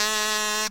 描述：在一个廉价的Radio Shack夹式电容前，有一个绿色塑料卡祖的多重采样。
Tag: 免费 卡祖笛 多重采样 样品 声音